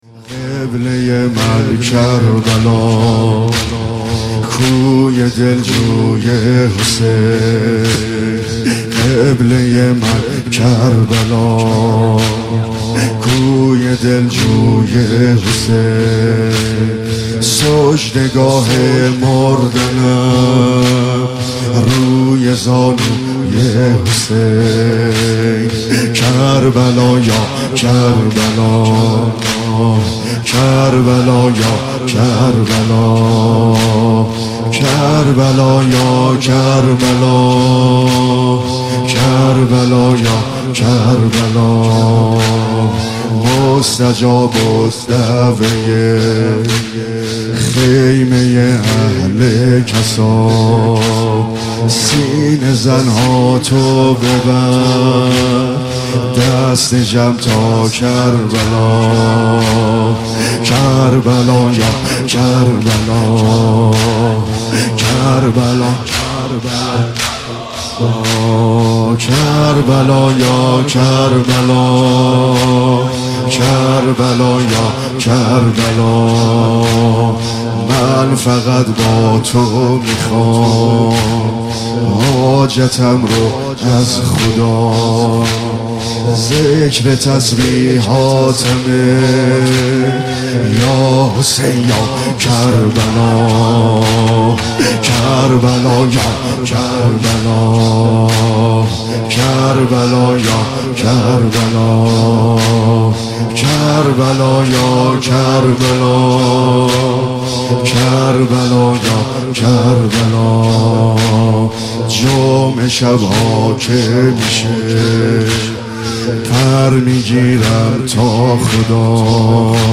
شورجدید